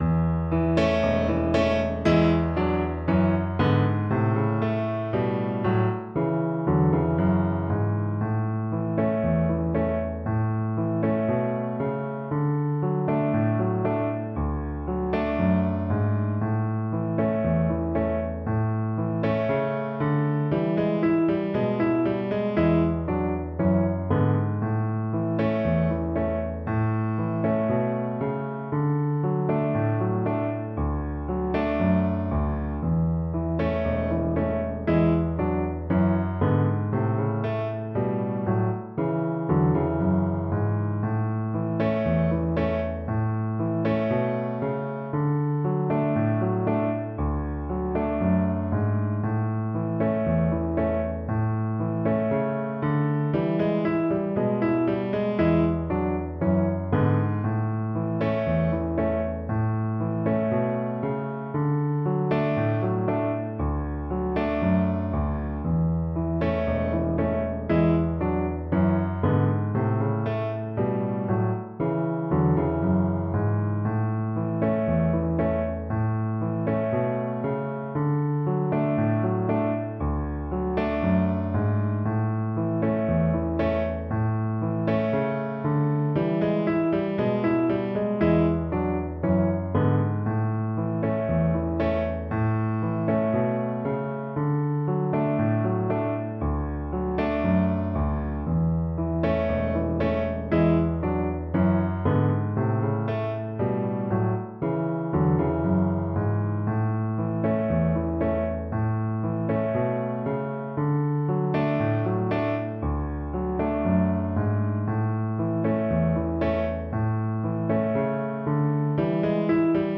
Play (or use space bar on your keyboard) Pause Music Playalong - Piano Accompaniment Playalong Band Accompaniment not yet available transpose reset tempo print settings full screen
Violin
4/4 (View more 4/4 Music)
A major (Sounding Pitch) (View more A major Music for Violin )
Moderato =c.90
Traditional (View more Traditional Violin Music)